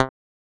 Abstract Click (1).wav